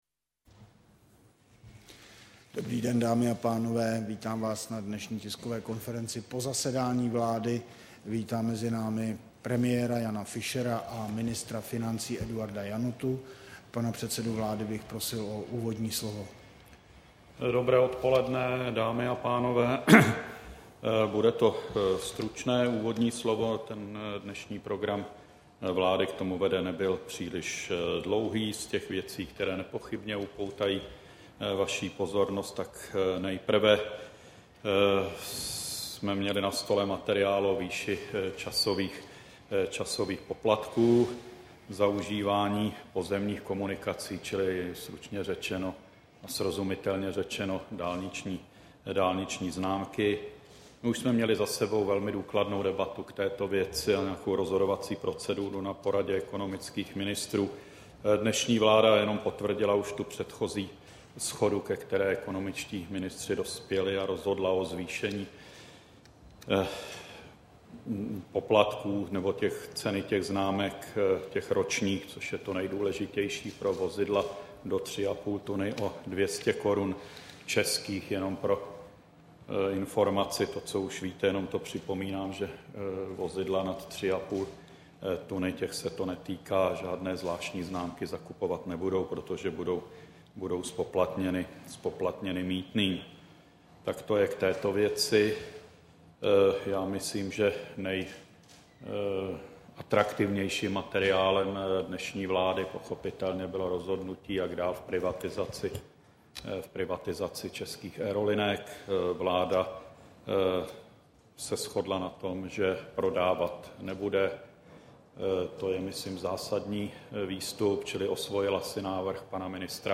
Tisková konference po zasedání vlády, 26. října 2009